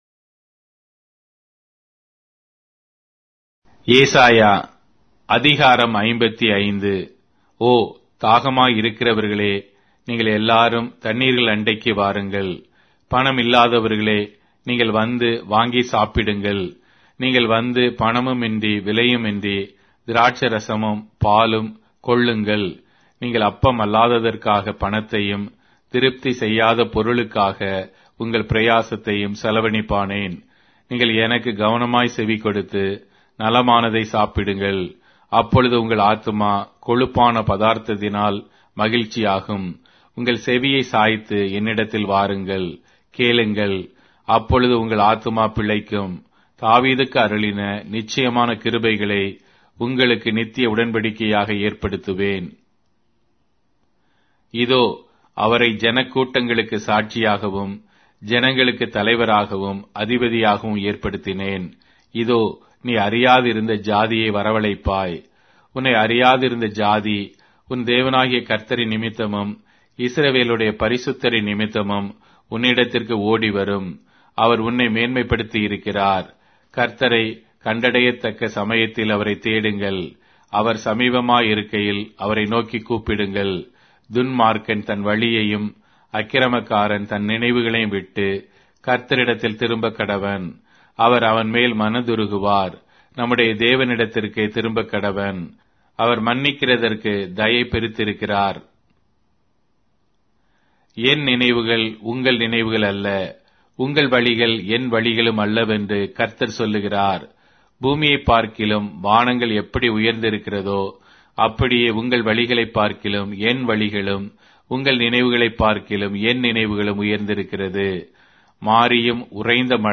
Tamil Audio Bible - Isaiah 23 in Ocvhi bible version